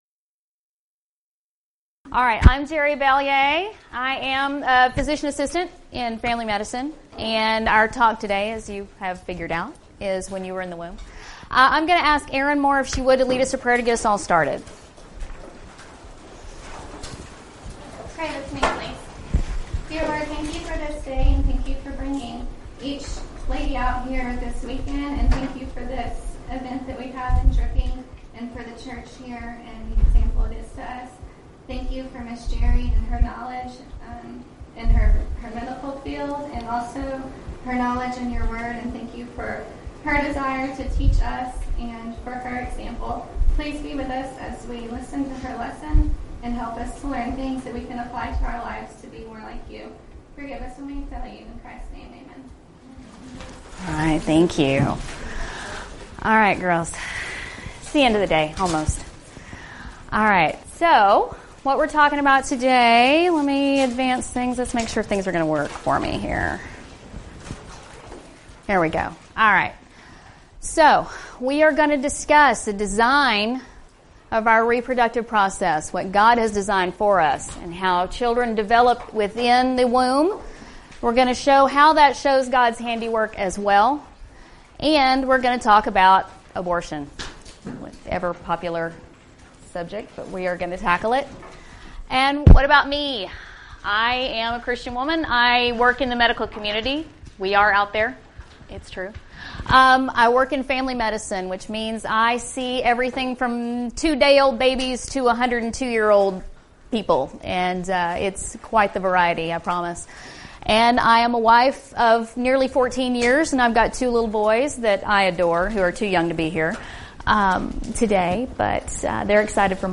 Event: 2014 Discipleship U
lecture